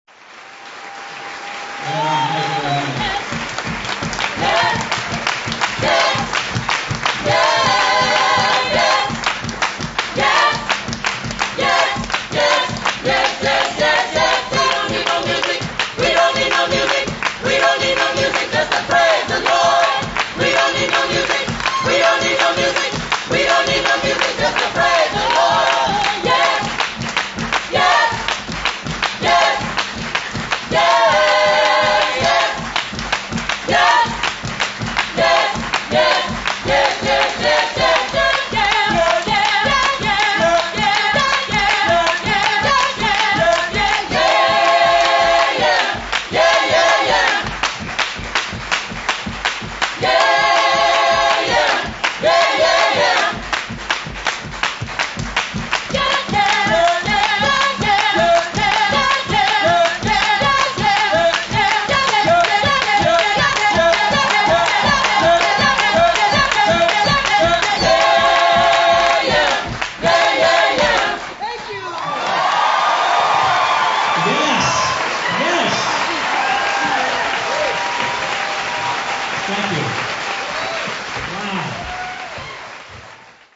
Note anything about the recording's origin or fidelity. November 11, 2000 at the Seattle Art Museum